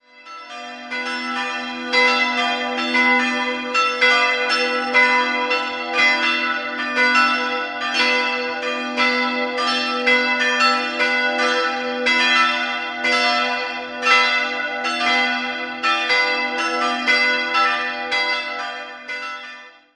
Beim Gnadenbild im Hochaltar handelt es sich um eine wertvolle spätgotische Madonnenfigur. 3-stimmiges TeDeum-Geläute: h'-d''-e'' Alle Glocken wurden 1956 von Friedrich Wilhelm Schilling in Heidelberg gegossen.